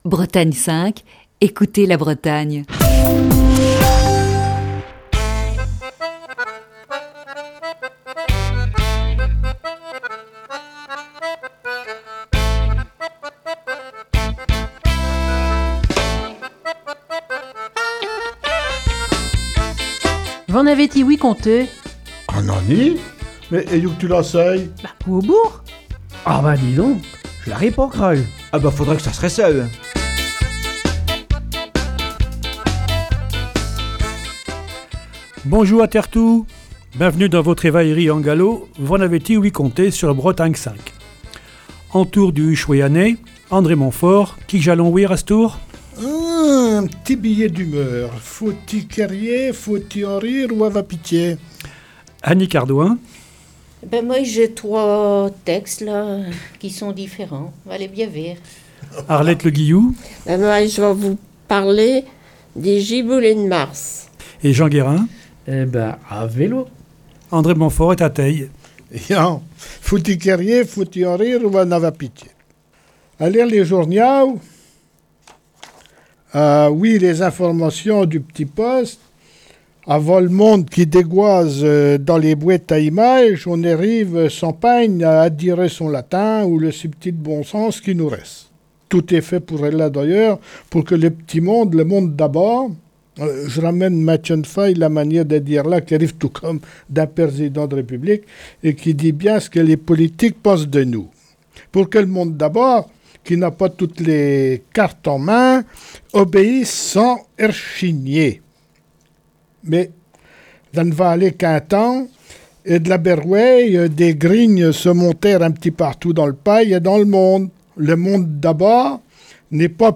Toute la troupe de V'en avez ti-ouï conté ? est là ce matin, réunie sur les ondes de Bretagne 5, pour parler de la solitude.